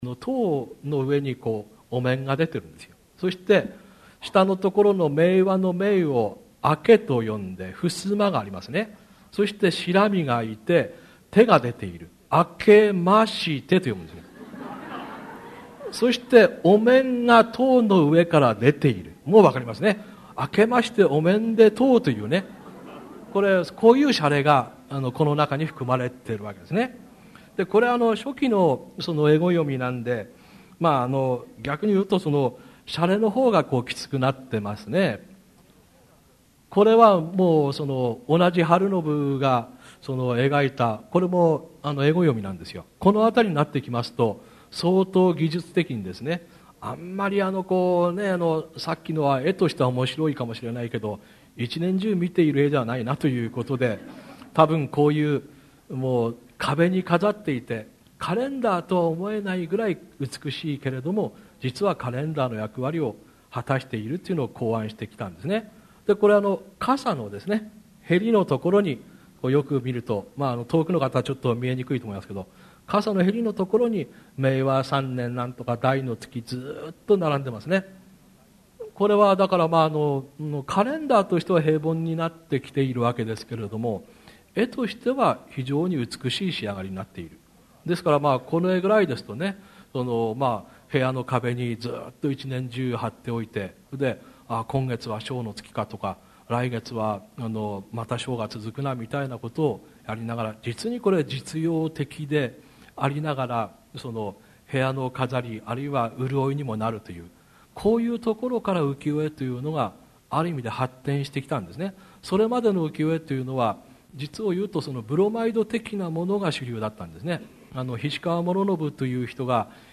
名だたる文筆家が登場する、文藝春秋の文化講演会。
図版資料もついて、目と耳と頭が喜ぶ愉快なギャラリートーク。
（2000年 千里阪急ホテル 梅花短期大学五十周年記念 文藝春秋文化講演会 講演原題「浮世絵の面白さ」より）